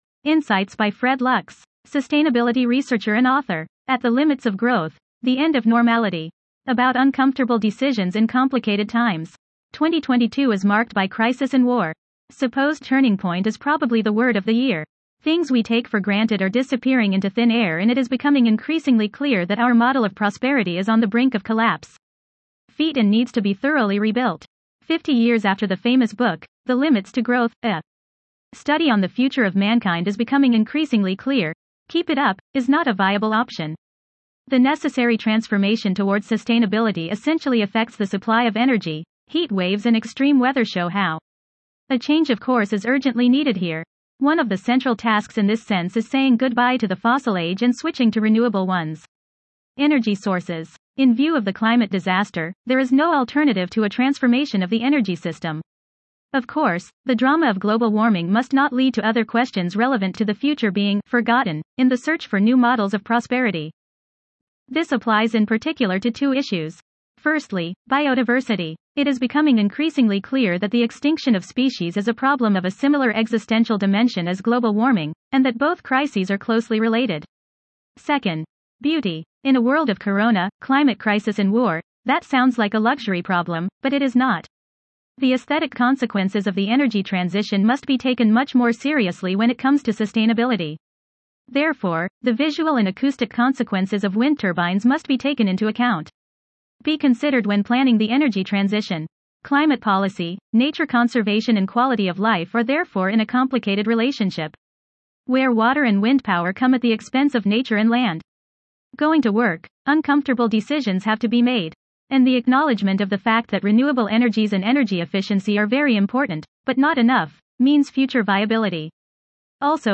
Einsichten Deutsch Weiblich Schwedisch Weiblich English Weiblich Italian Weiblich 2 views Share Download How was the audio ?